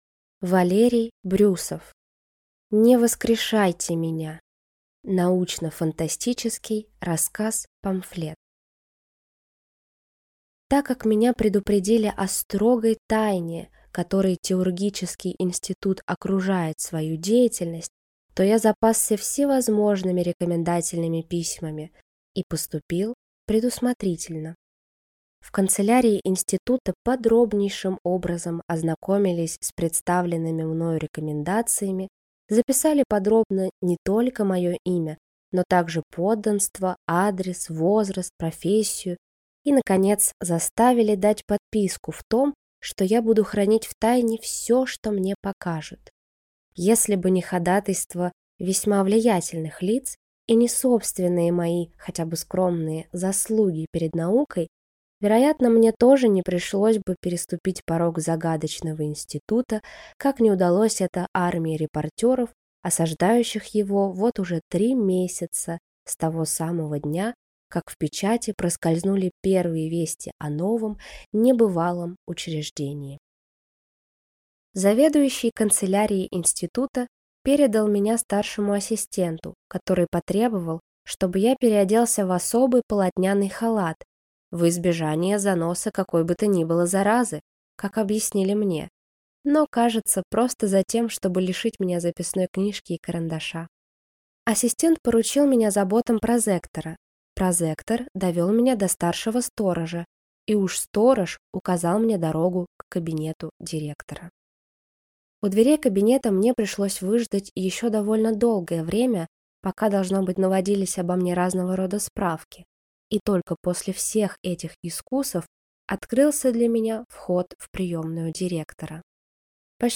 Аудиокнига Не воскрешайте меня!